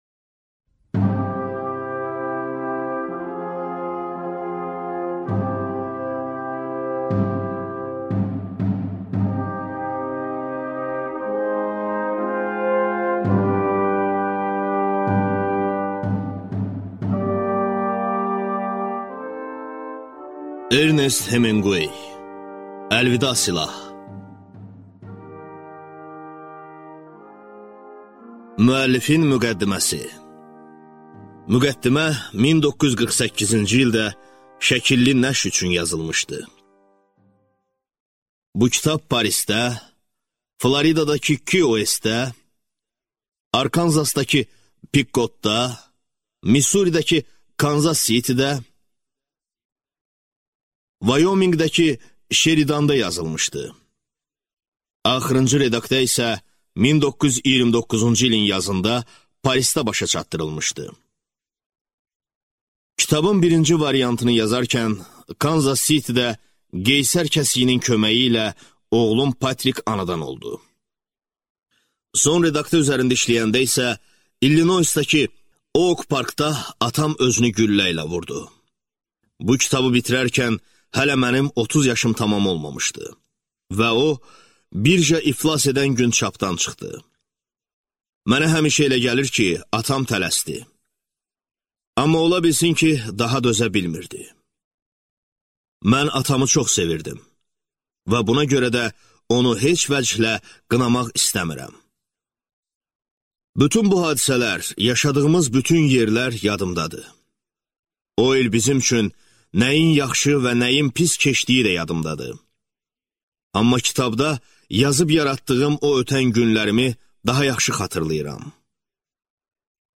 Аудиокнига Əlvida silah | Библиотека аудиокниг